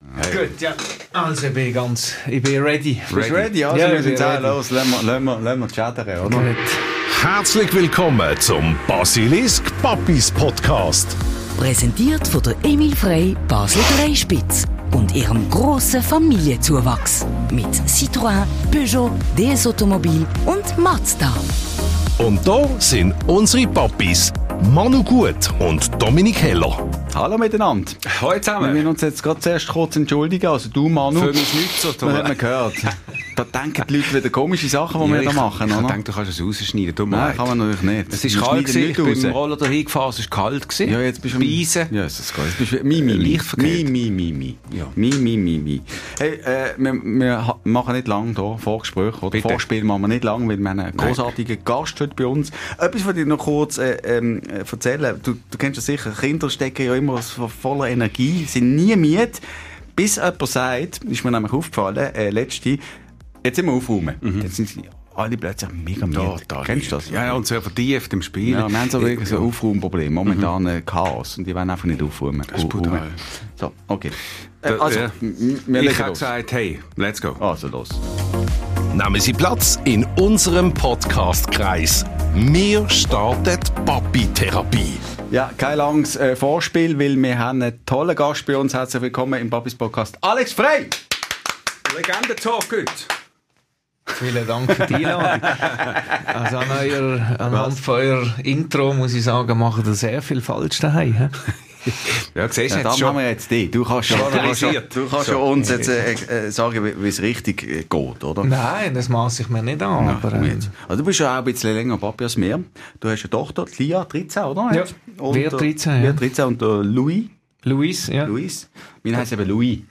Der höchste Papi von Basel lässt sich im Papis Podcast therapieren. Regierungspräsident Conradin Cramer ist in Folge #9 bei uns zu Gast.